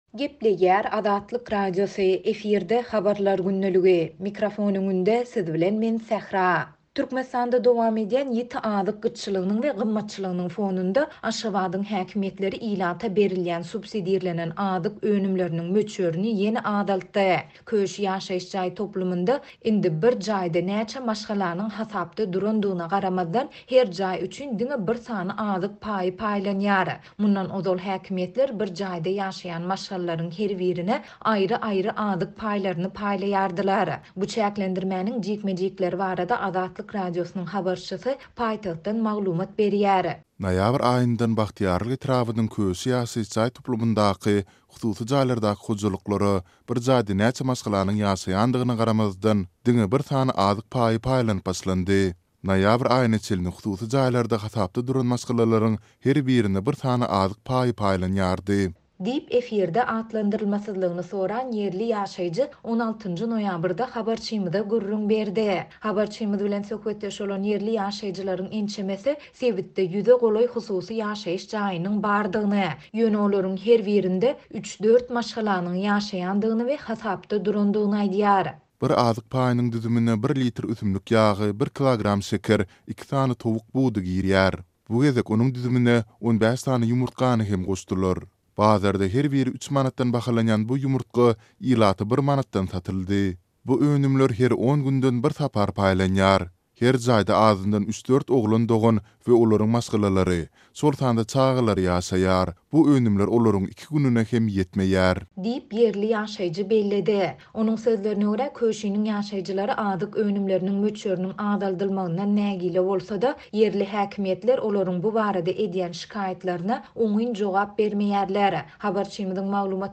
Paýtagtyň Parahat-7 etrapçasynda ösümlik ýagy azyk paýynyň düzüminden çykaryldy. Bu çäklendirmäniň jikme-jikleri barada Azatlyk Radiosynyň habarçysy paýtagtdan maglumat berýär.